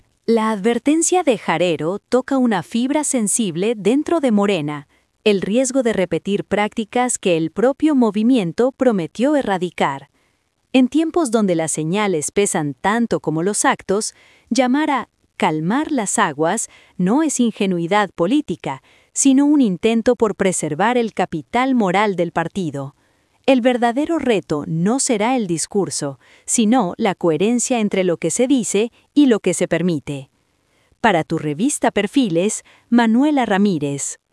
Durante un encuentro con medios, el legislador fue cuestionado sobre la utilización de recursos antes de tiempo en favor de eventuales aspirantes, a lo que respondió que no solo los recursos gubernamentales deben mantenerse al margen, sino también los del propio partido, al considerar que ambos tienen un origen público.